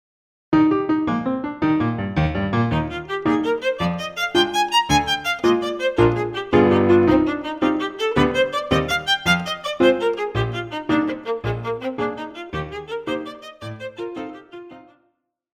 古典
鋼琴
獨奏與伴奏
有主奏
有節拍器